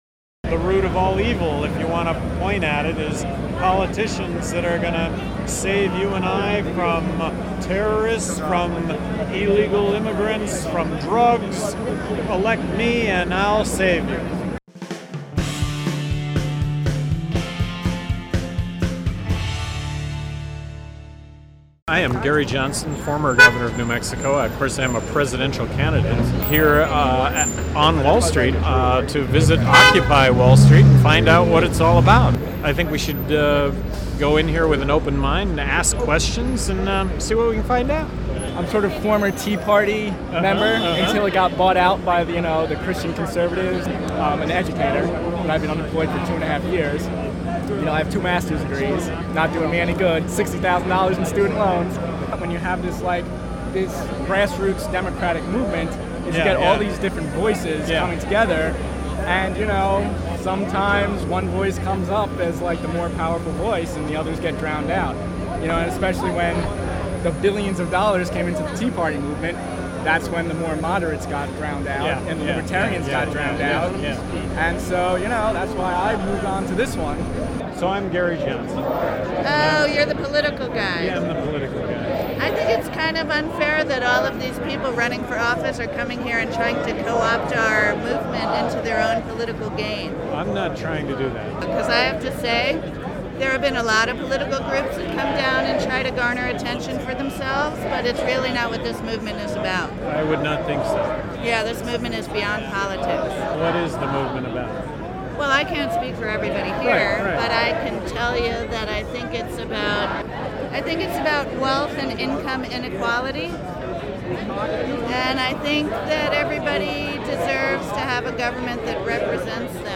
Johnson was responding to a question put to him on Tuesday, October 18, as he talked with Occupy Wall Street protesters in Manhattan's Zuccotti Park.